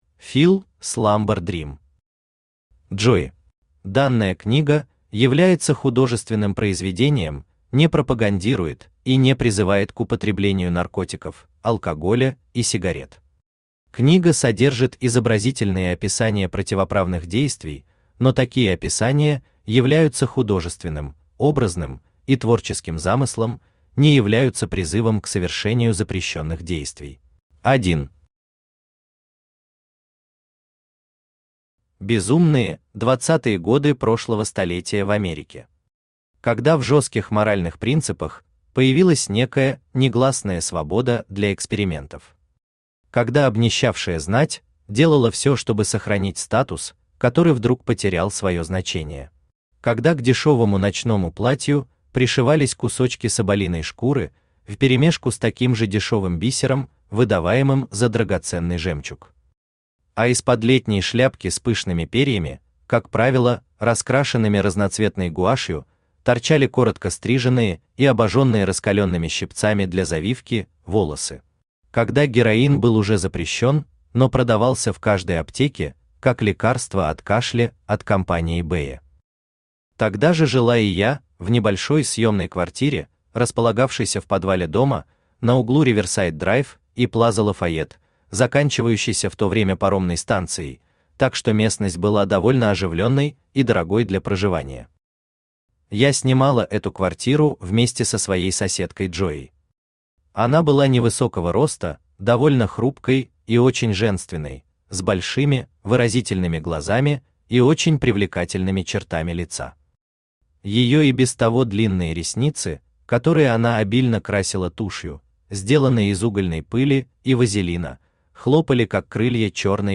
Aудиокнига Джои Автор Feel Slumber-Dream Читает аудиокнигу Авточтец ЛитРес.